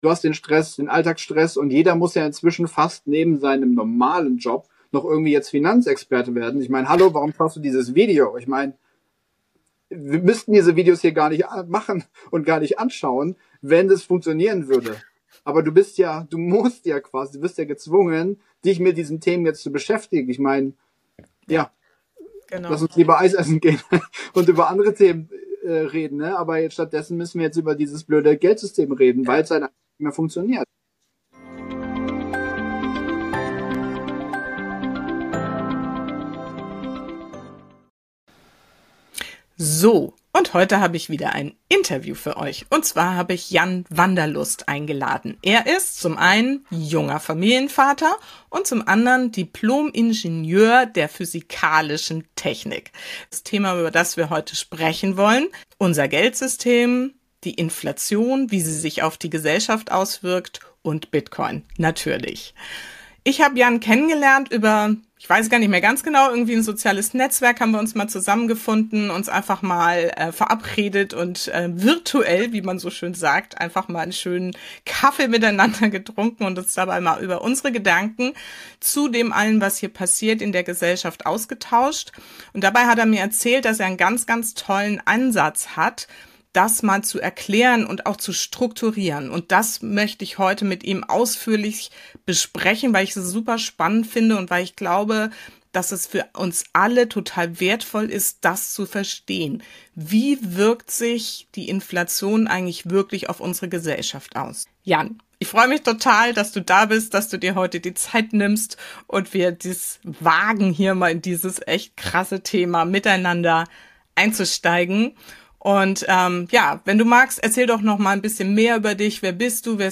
Ein zentrales Modell in unserem Gespräch ist die Maslowsche Bedürfnispyramide.